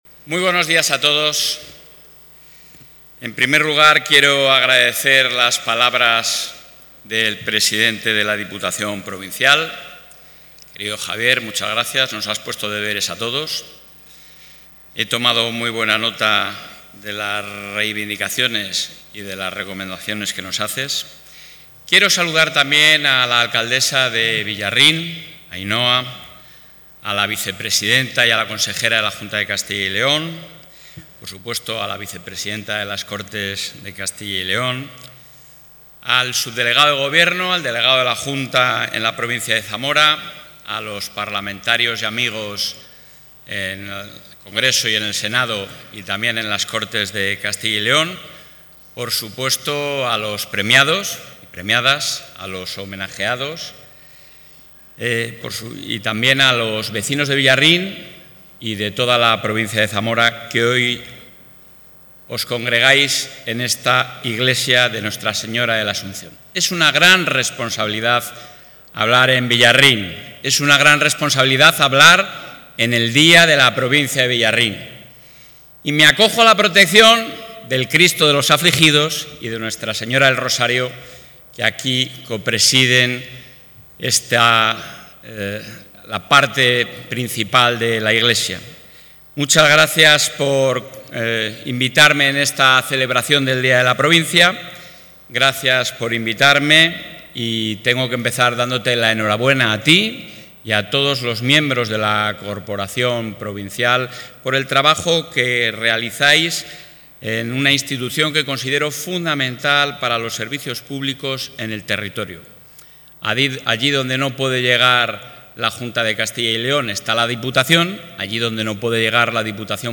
El presidente de la Junta de Castilla y León, Alfonso Fernández Mañueco, ha participado hoy en el acto conmemorativo del...
Intervención del presidente de la Junta.